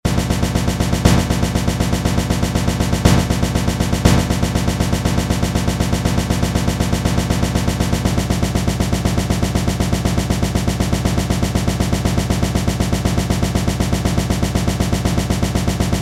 gunshots times 1,000,000 Sound Button: Unblocked Meme Soundboard